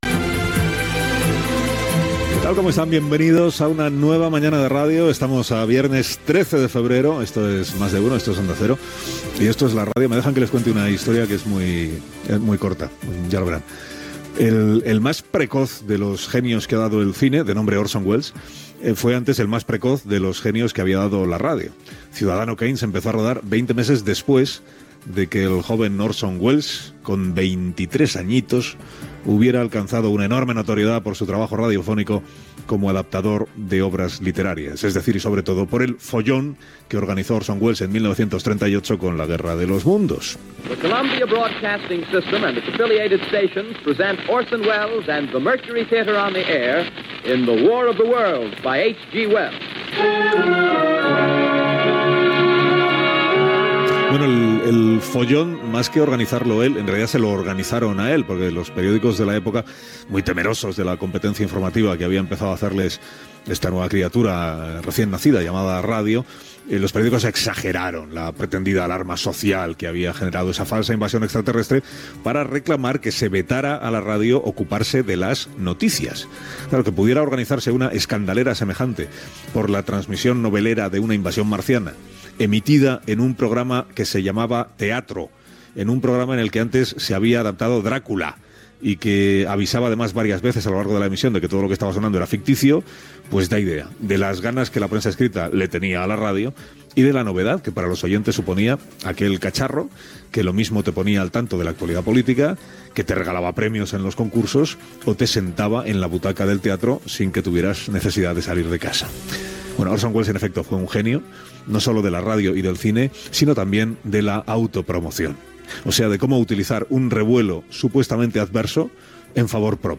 Monòleg de Carlos Alsina en el Dia Mundial de la Ràdio, sobre "La guerra dels móns" d'Orson Welles.
Info-entreteniment